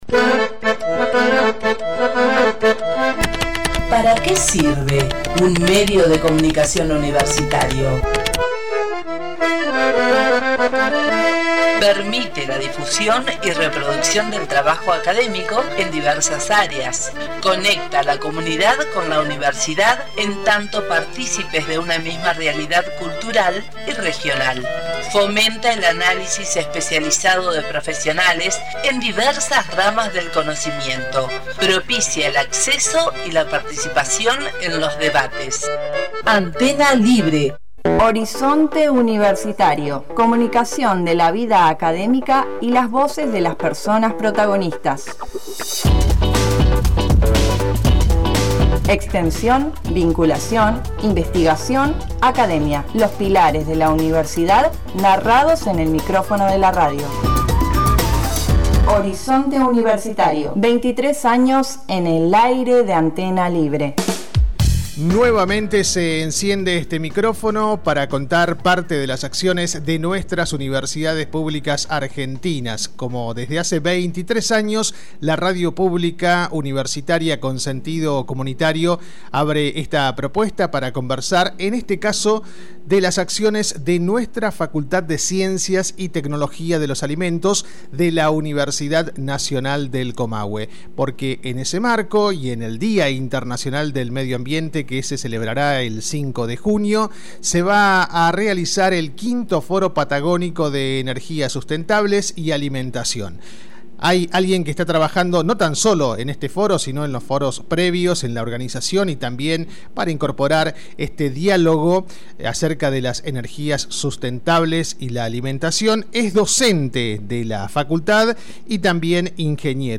En Horizonte Universitario entrevistamos